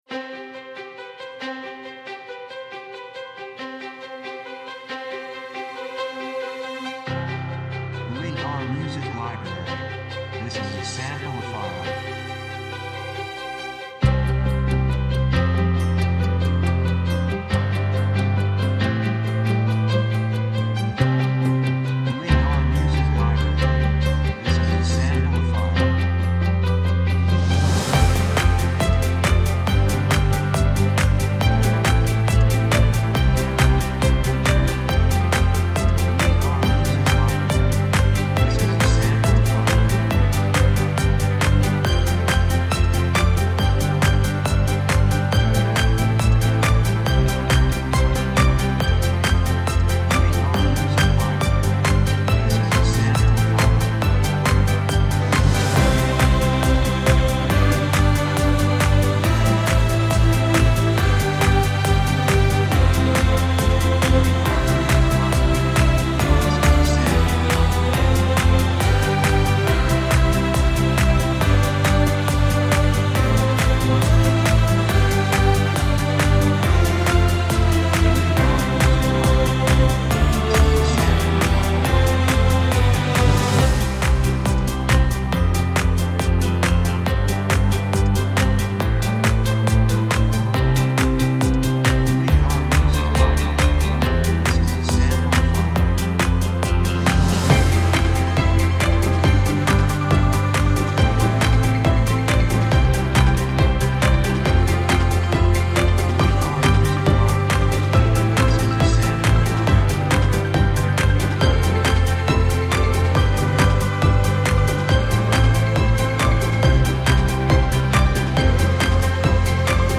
3:12 138 プロモ, スコア
雰囲気幸せ, 高揚感, 決意, 喜び
曲調ポジティブ
楽器ピアノ, ストリングス, ボーカル, 手拍子
サブジャンルドラマ, オーケストラハイブリッド
テンポ速い